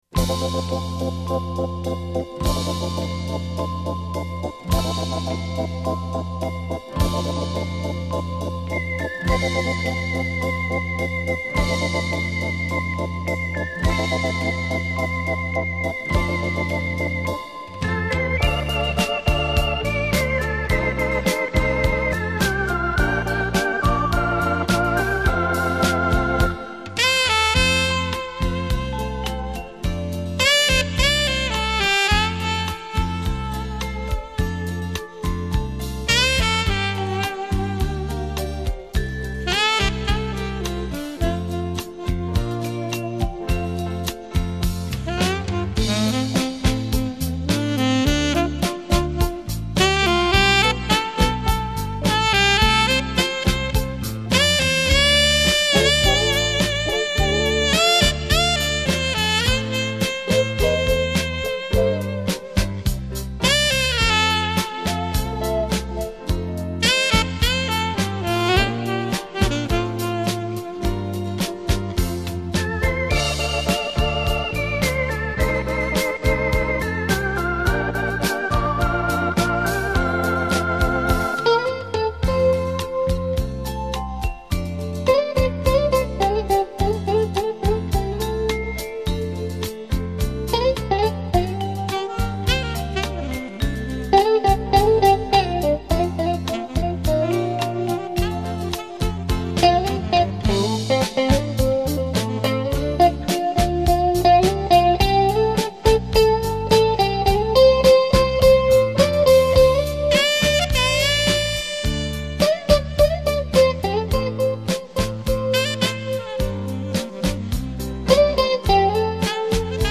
錄音：佳聲錄音室
閒適優雅的音符，完美傳真的音質，
優美動聽的旋律讓人沉醉其中...